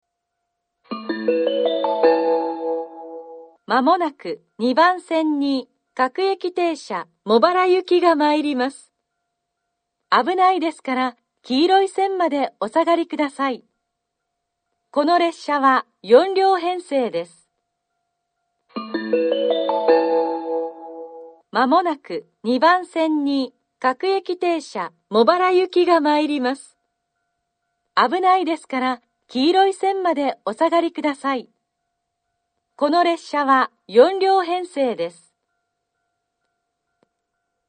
２番線下り接近放送 各駅停車茂原行（４両）の放送です。